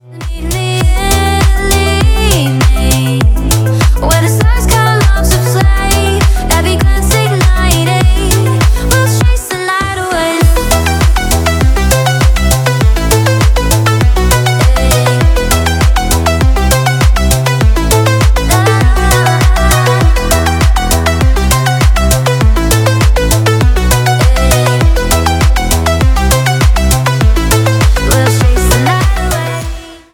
2025 » Клубные » Танцевальные Скачать припев